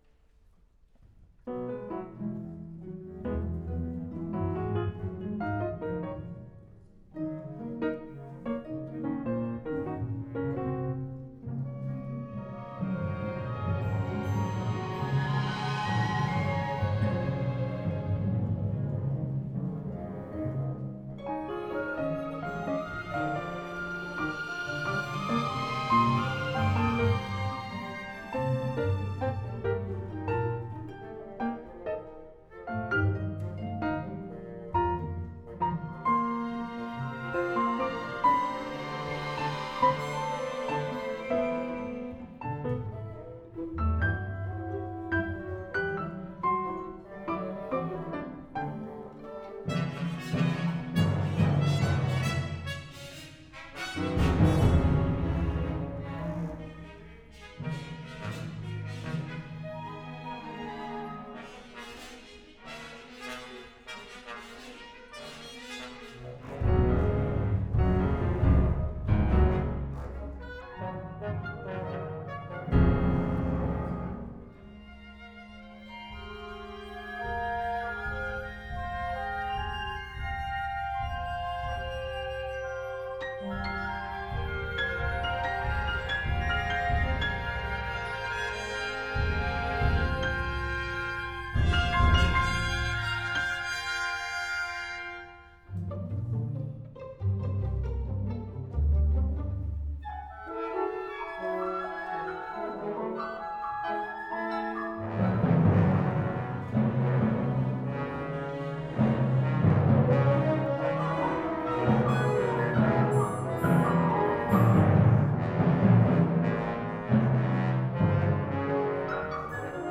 Full orchestra
I composed Hereafter Scattering as a nest around a musical idea presented three times throughout the piece—a delicate pizzicato fragment. By surrounding those plucked string passages with woven and scattering material, the idea becomes a familiar refrain from the magnitude of a full orchestra.
Read and recorded by the Aspen Conducting Orchestra in August 2019.